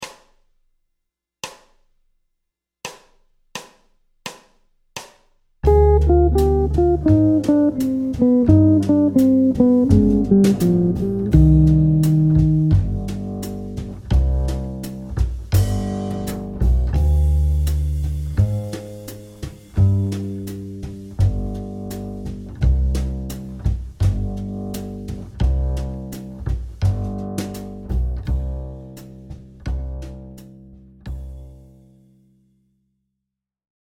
Utilisation de la gamme de C mineur harmonique aussi bien sur la Sous-dominante que sur la Dominante.